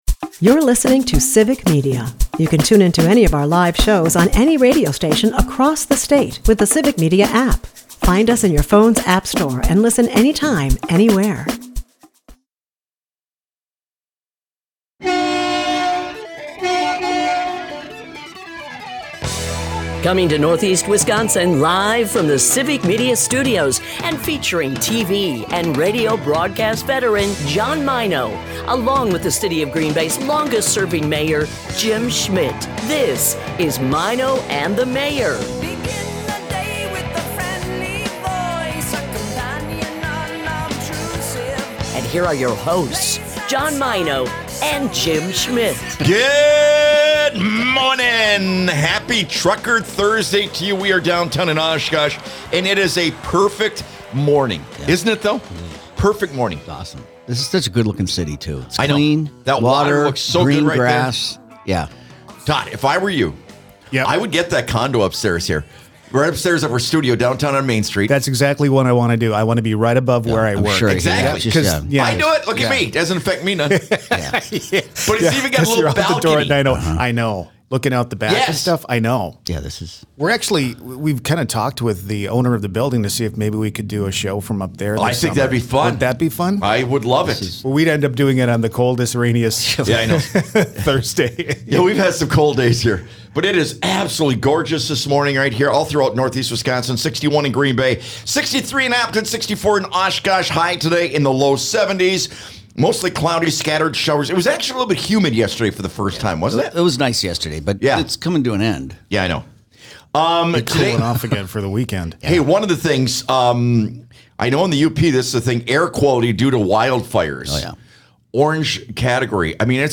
The show from Oshkosh starts off with discussions of how the Mayor is using Ozempic to maintain. The show features a humorous exchange with "Marv Albert" to celebrate Marv's birthday. Key highlight: Waterfest in Oshkosh kicks off with the bombastic 80s tribute: Hairball.